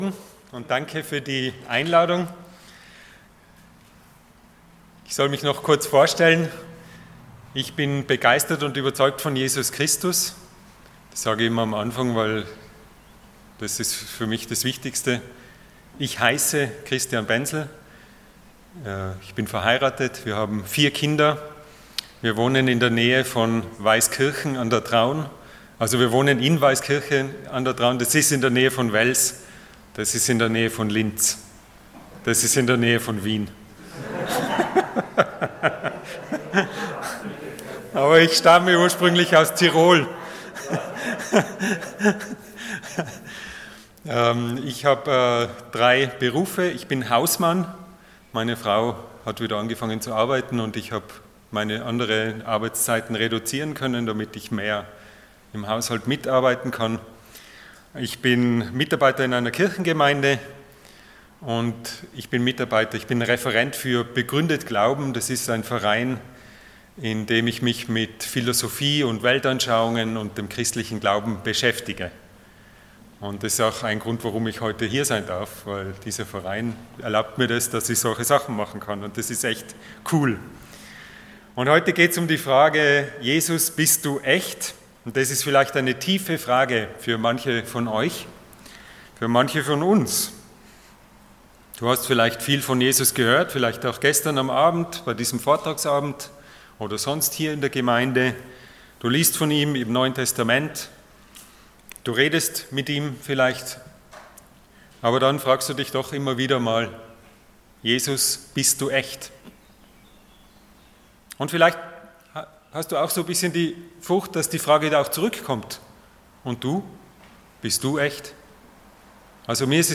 Allgemeine Predigten Passage: Matthew 11:2-6 Dienstart: Sonntag Morgen %todo_render% Jesus